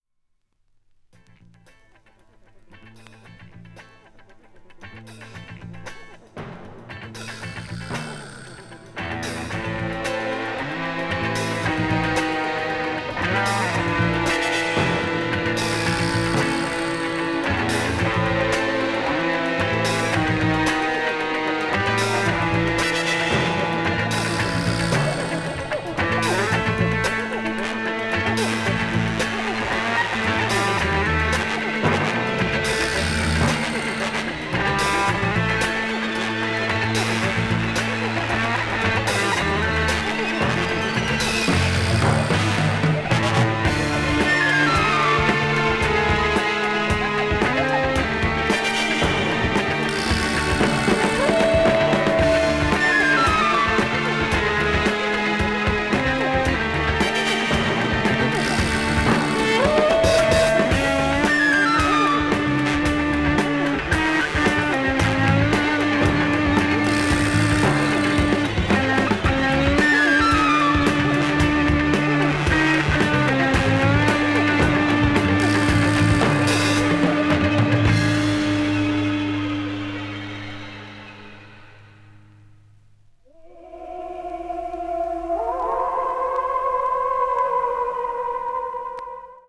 サウンドトラック
media : EX-/EX-(わずかなチリノイズ/一部軽いチリノイズが入る箇所あり,軽いプチノイズ数回あり)